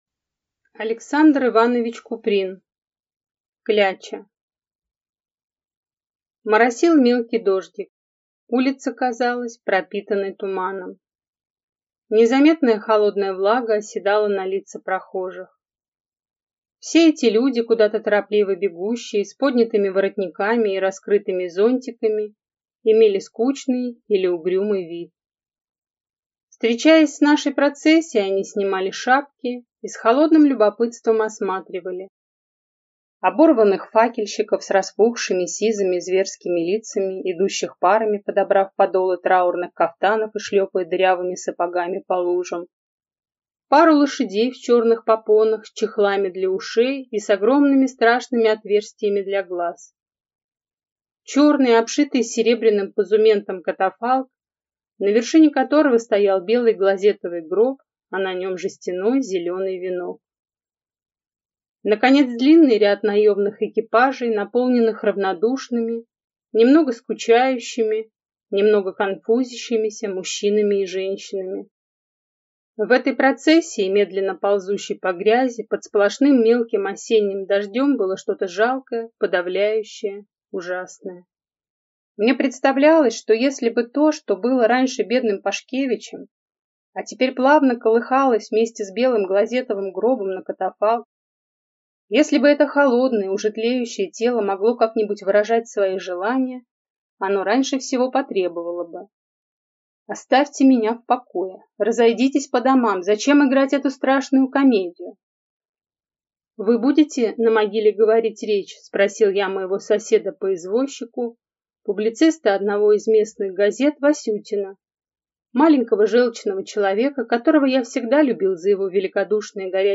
Аудиокнига Кляча | Библиотека аудиокниг